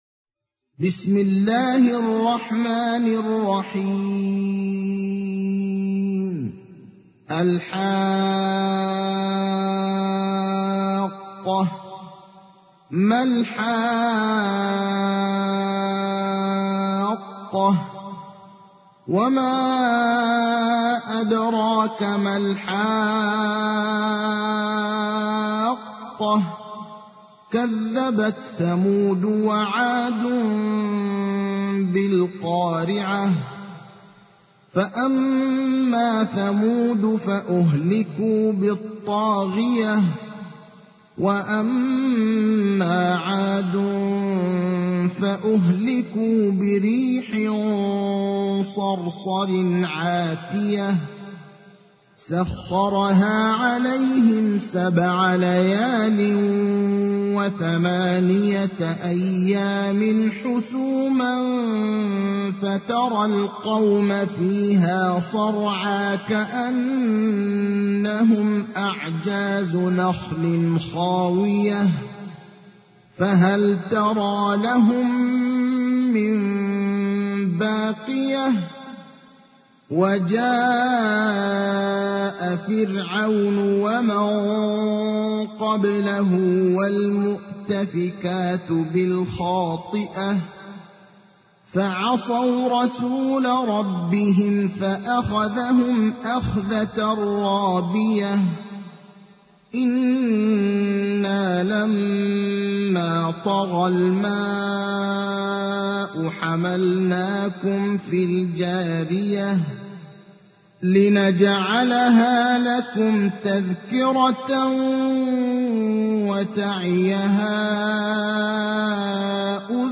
تحميل : 69. سورة الحاقة / القارئ ابراهيم الأخضر / القرآن الكريم / موقع يا حسين